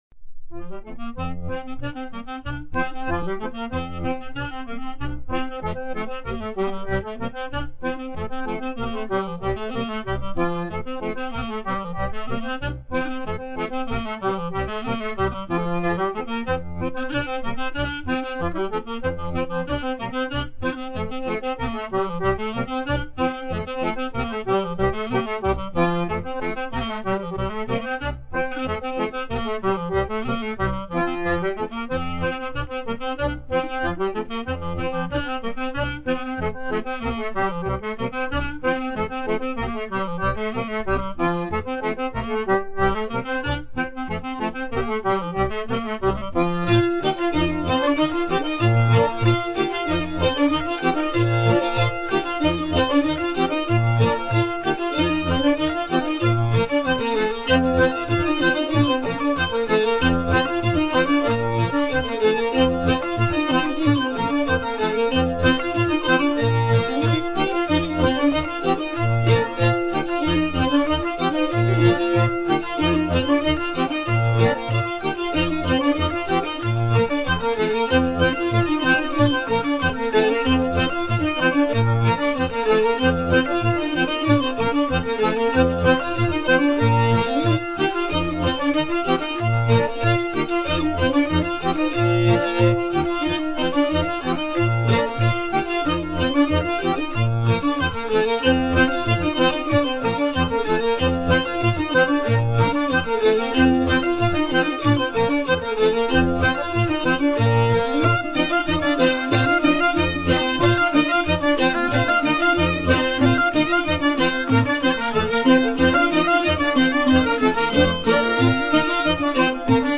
Airs du répertoire des sonneurs de couple vielle-bombarde
danse : rond
Pièce musicale éditée